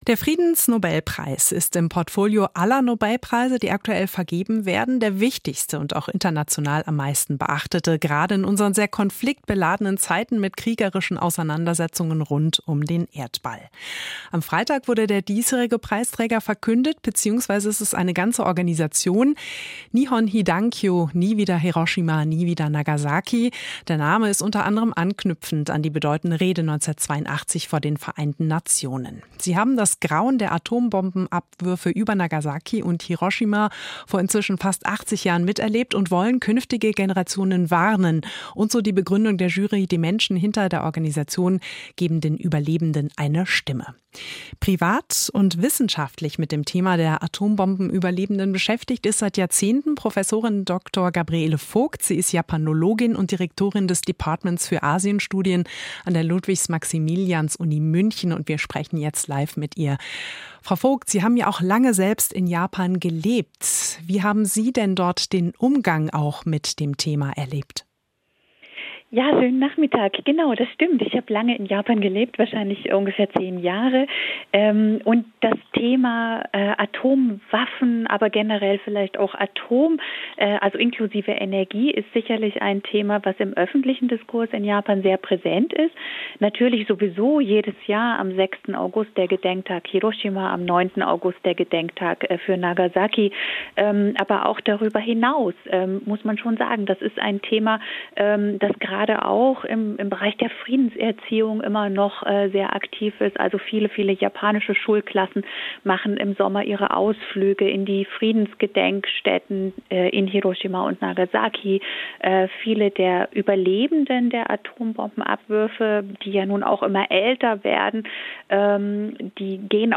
Hintergrundgespräch